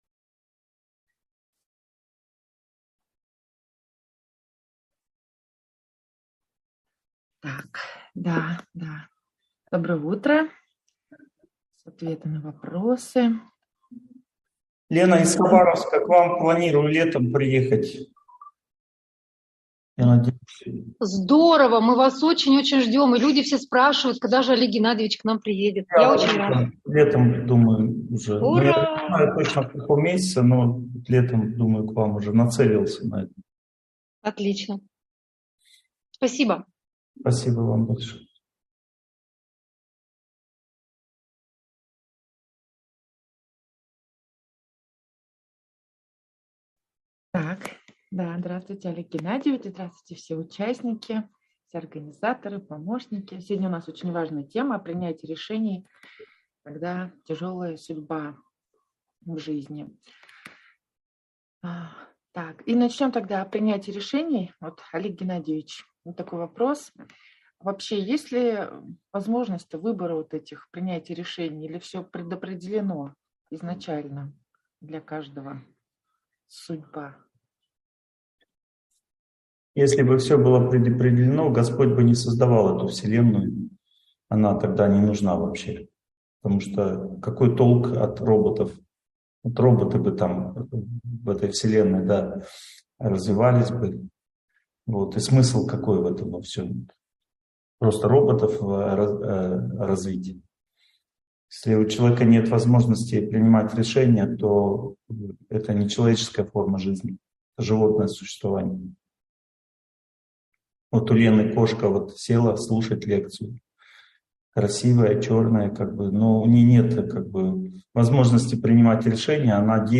Принятие решений во время жестокой судьбы (онлайн-семинар, 2023)